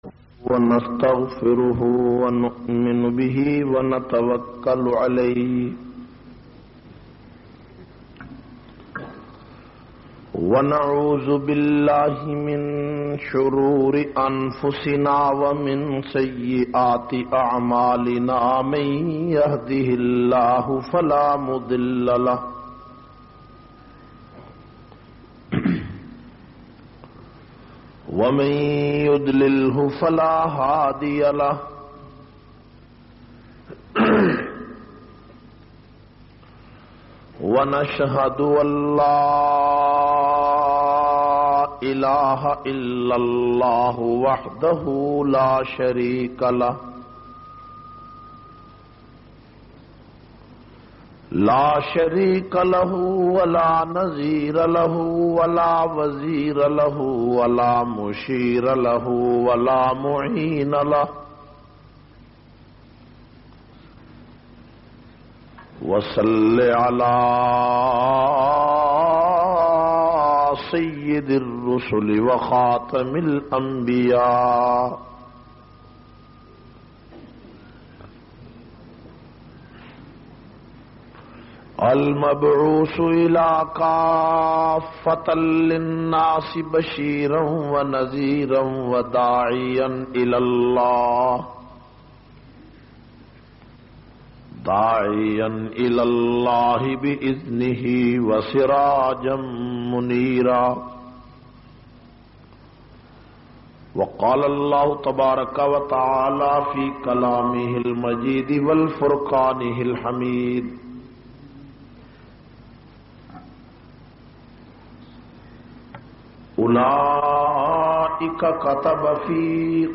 23- Bhai bhai conference Masjid Qasim Ali Khan Peshawar.mp3